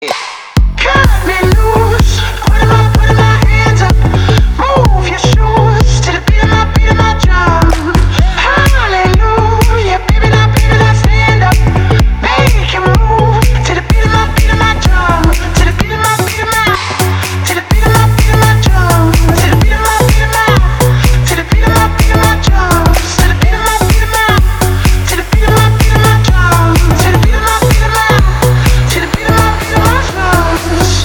• Качество: 320, Stereo
мужской голос
dance
Electronic